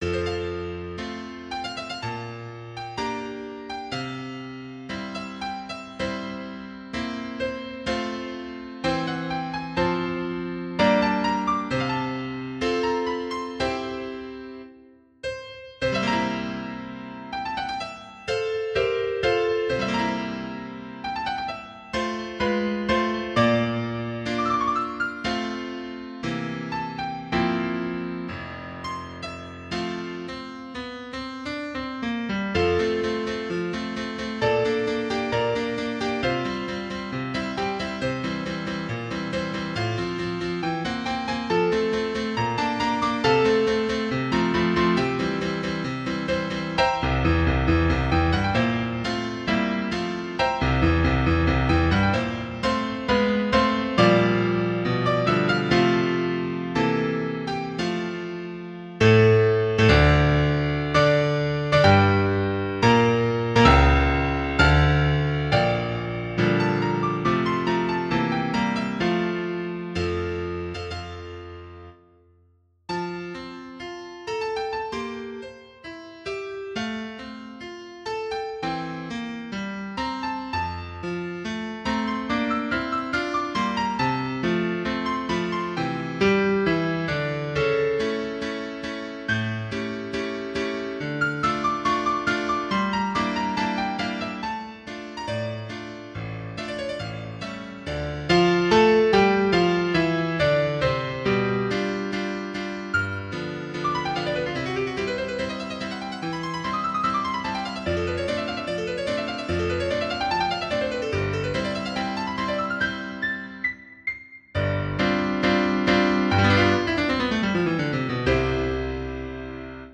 浪漫曲（F大调）